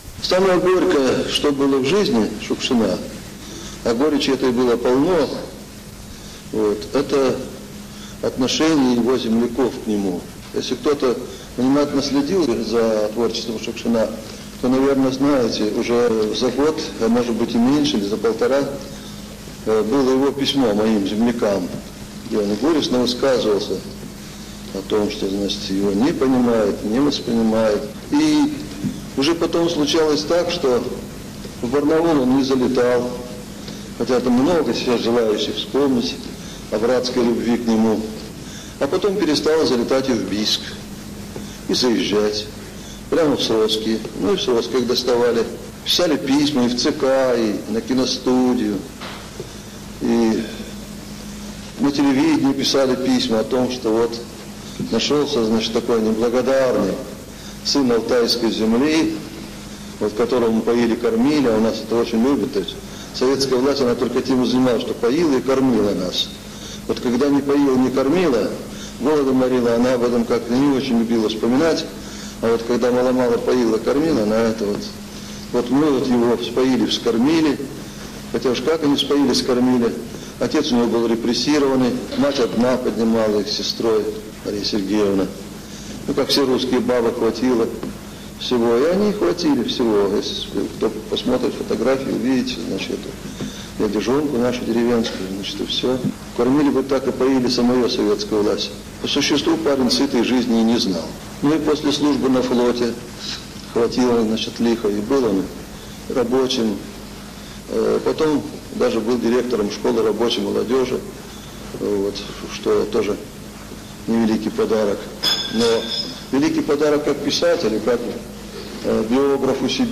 Голос Астафьева. Размышления о Шукшине | Библиотека-музей В.П. Астафьева город Дивногорск Красноярский край
В аудиофайле, приложенном к этому материалу, - рассказ-размышление Виктора Петровича Астафьева о Василии Макаровиче Шушкине.